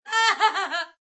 Descarga de Sonidos mp3 Gratis: risa 3.